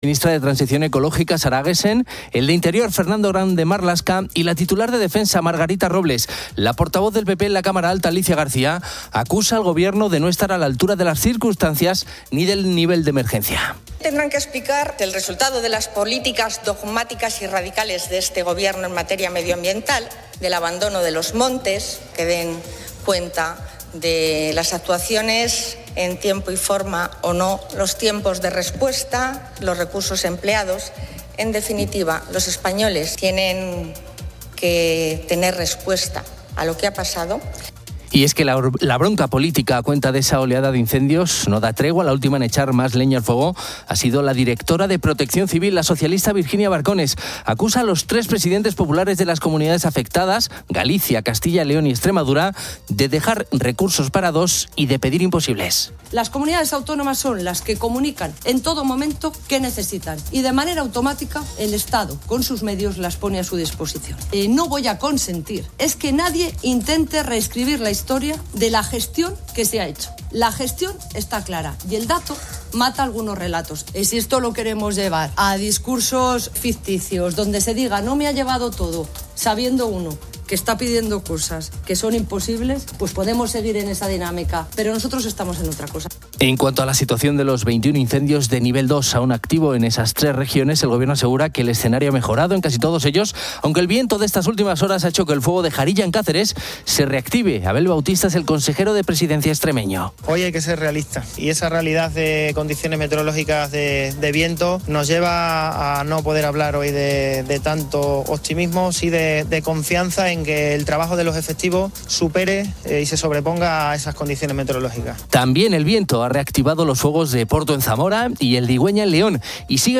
Se subraya la importancia del ejercicio físico para personas mayores a través de una entrevista con un experto, quien desmiente mitos y resalta los beneficios para la salud y autonomía. Los oyentes también comparten sus series de televisión veraniegas favoritas.